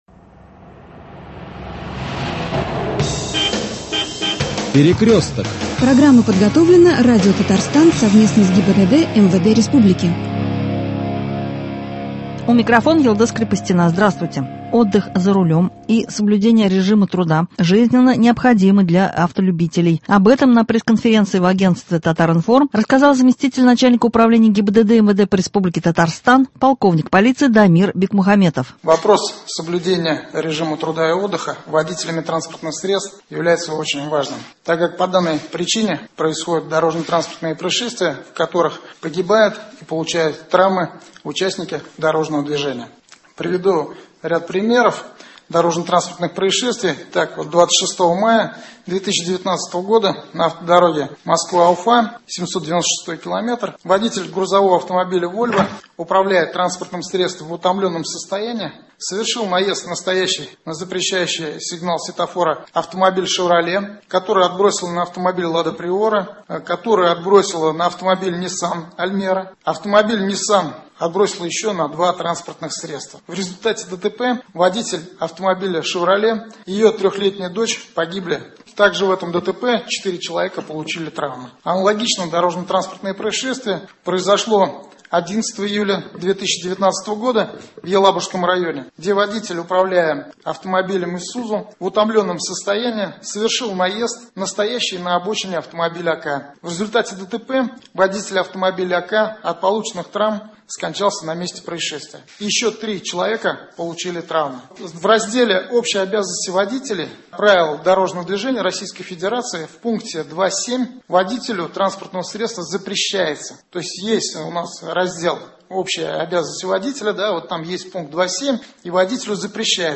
Сон и соблюдение режима труда и отдыха- как фактор безопасности на дорогах. Выступление зам. нач. УГИБДД МВД по РТ Дамира Бикмухаметова.